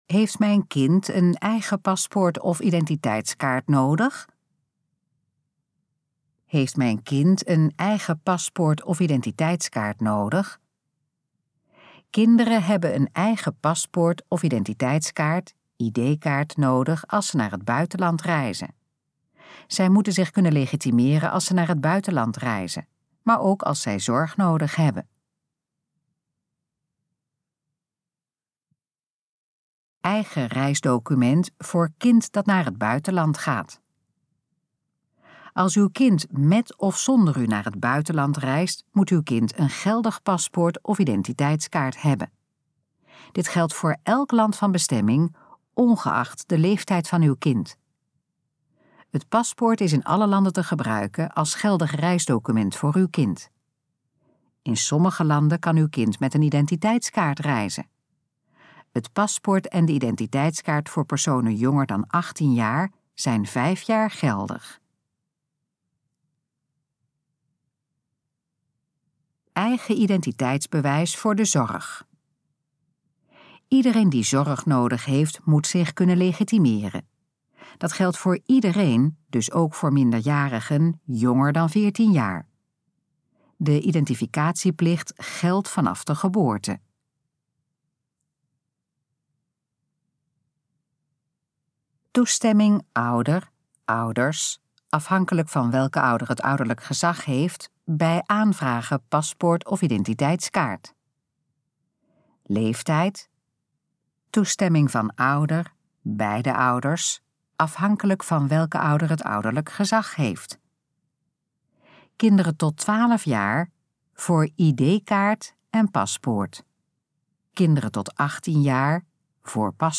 Gesproken versie van: Heeft mijn kind een eigen paspoort of identiteitskaart nodig?
Dit geluidsfragment is de gesproken versie van de pagina Heeft mijn kind een eigen paspoort of identiteitskaart nodig?